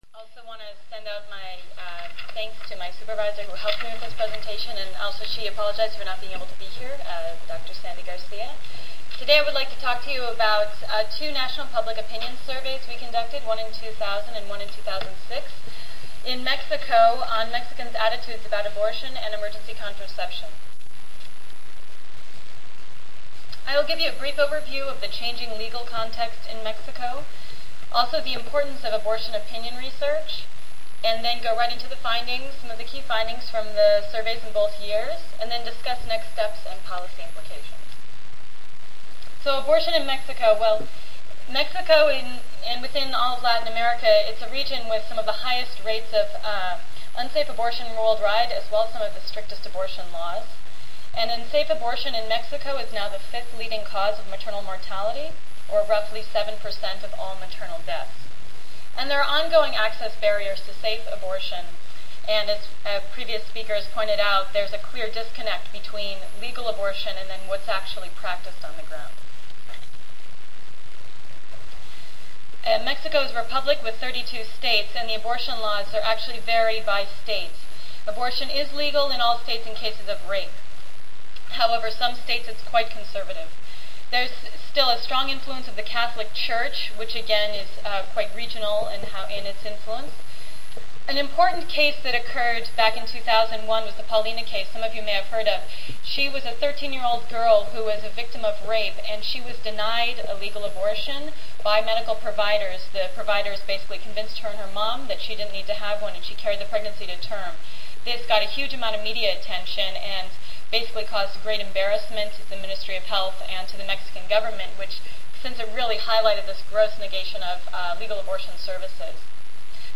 Two panelists explore abortion in Mexcio.